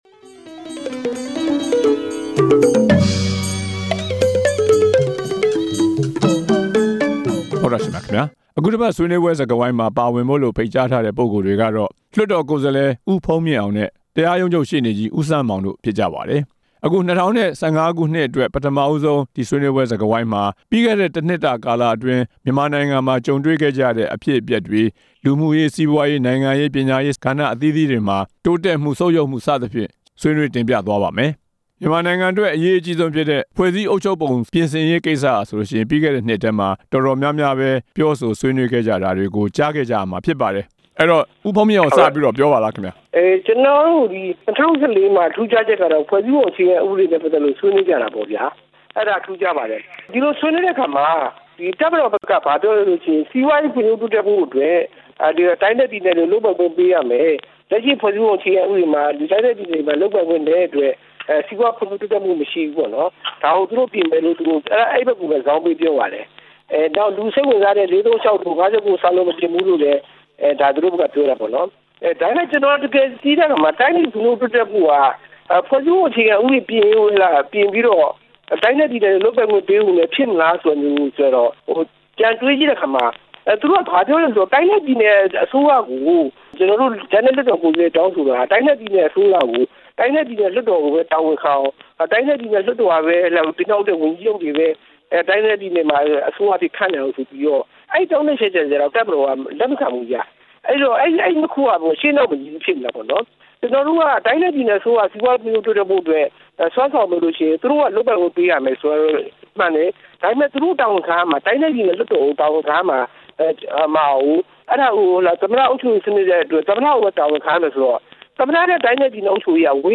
ဆွေးနွေးပွဲစကားဝိုင်း